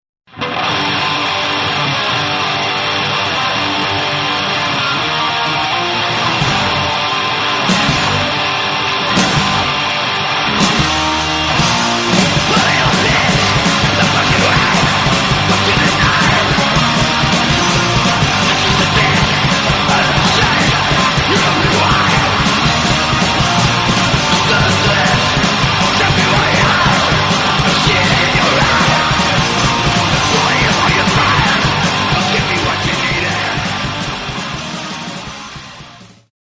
Canadian Black Speed Metal at its finest!!!